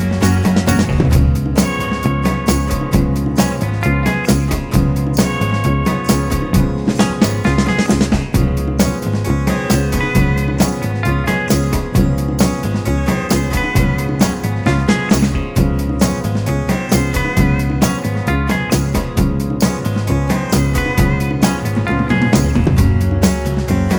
Guitars Only Mix Pop (1960s) 3:35 Buy £1.50